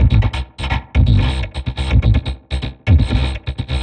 tx_perc_125_scrunch.wav